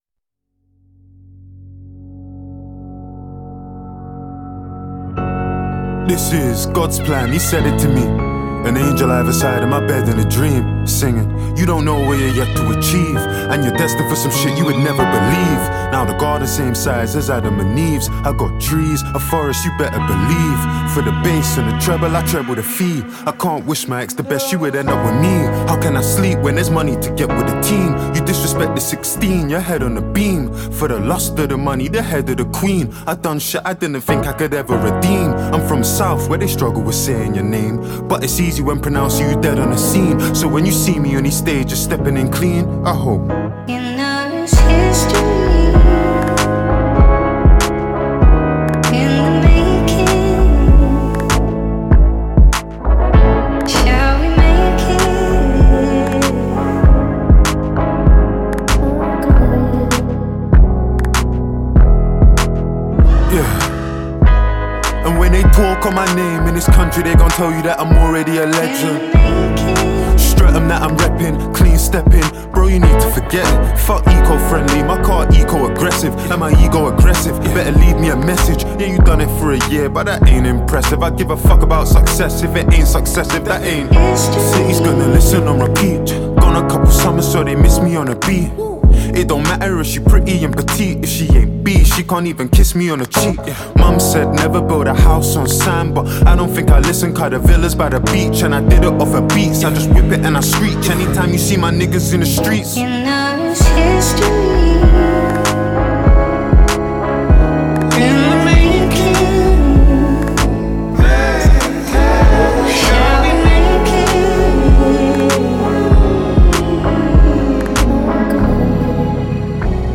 British rapper